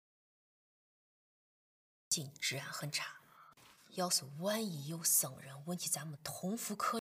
佟湘玉AI语音：地道陕西口音生成器
文本转语音
陕西口音
富有表现力的人声
我们的AI提供真实、类人的陕西口音，清晰地表达了与佟湘玉相关的特定地域音调。通过音高和节奏的细微变化，它模仿了原始角色表演的细微差别。